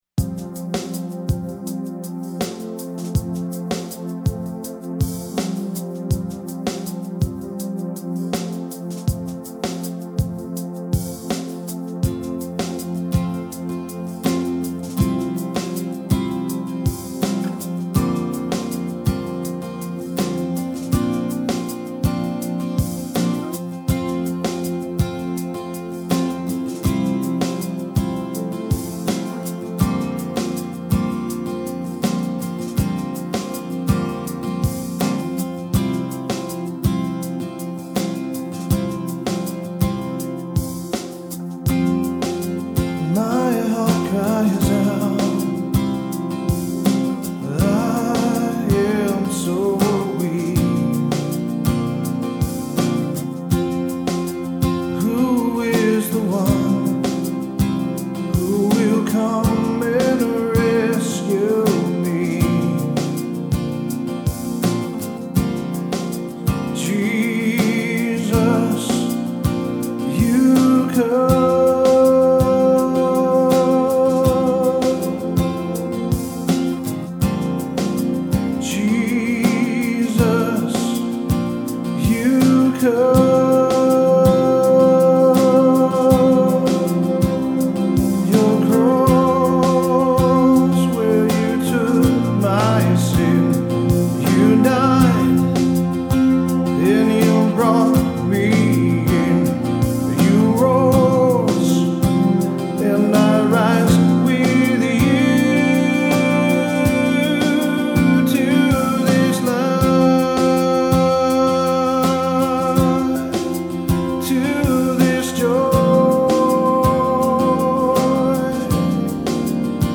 recording and mastering studio